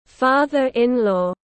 Bố vợ (bố chồng) tiếng anh gọi là father-in-law, phiên âm tiếng anh đọc là /ˈfɑː.ðər.ɪn.lɔː/.